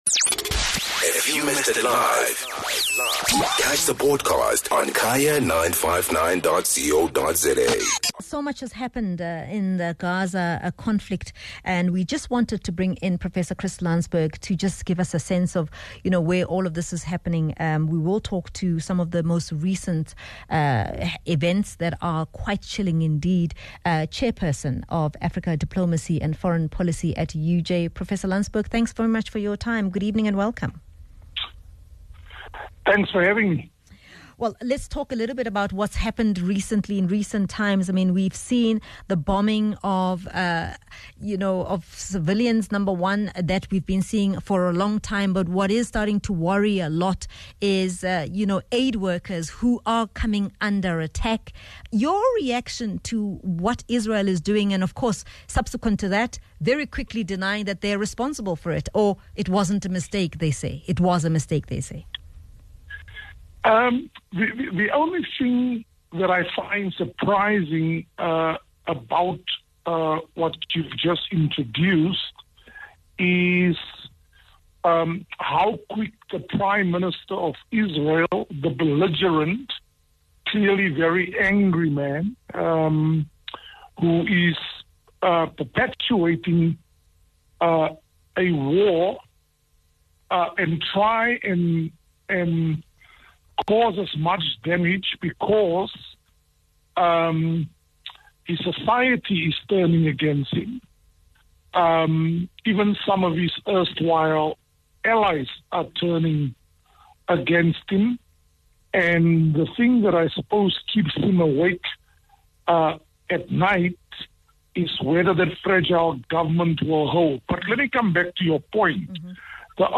International news update